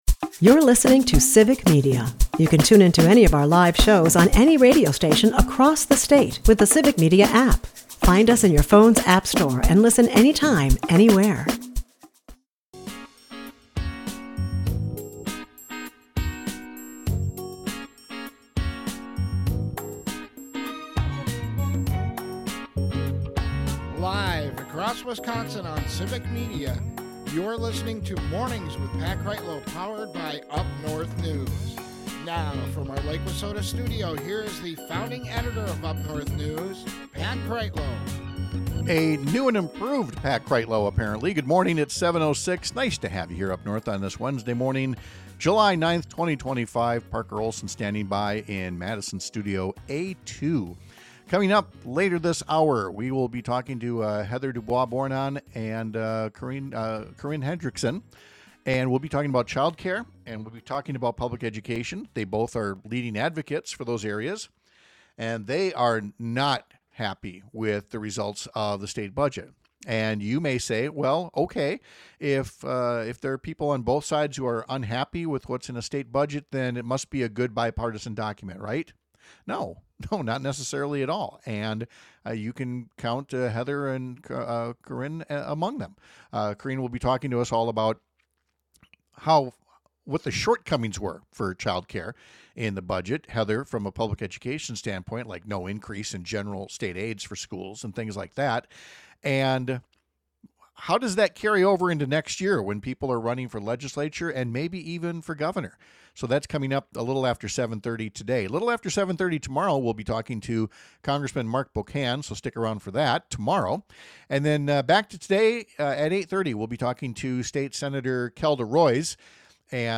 Panel: The Future of Public Education in Wisconsin